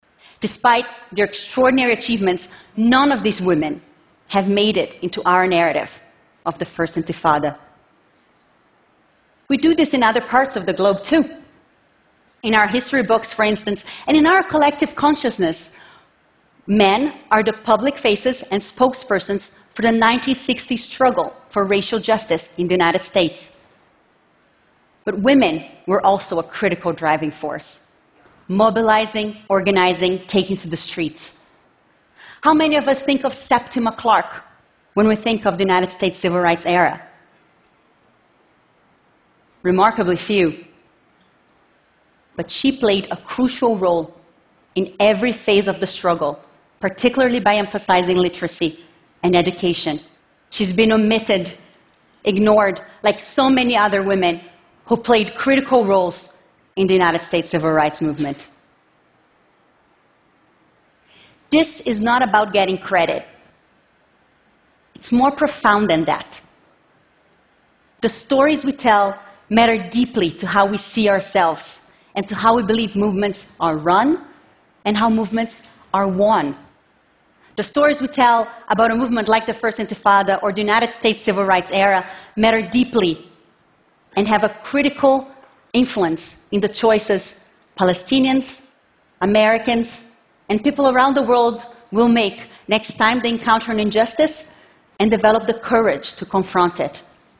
TED演讲:女性是怎样发起非暴力冲突的(7) 听力文件下载—在线英语听力室